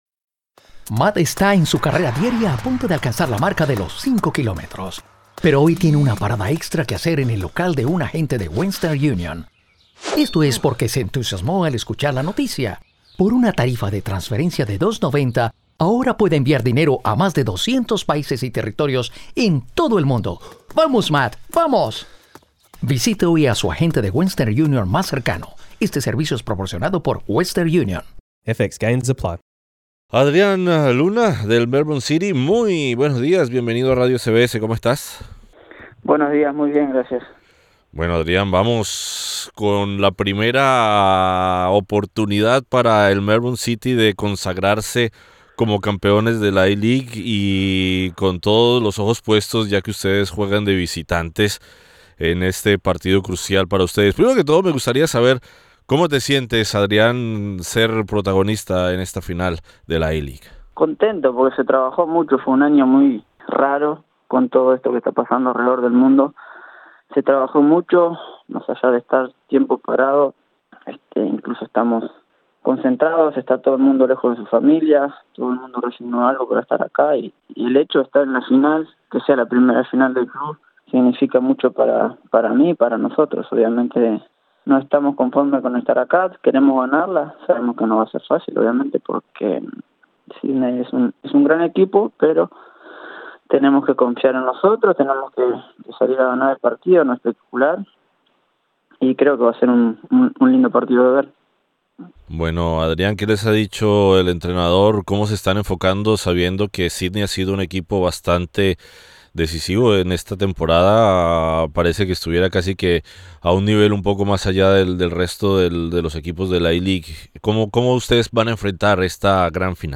Para el City, uno de sus protagonistas será el delantero de origen uruguayo, Adrián Luna, quien converso con nosotros en exclusiva en la previa del partido.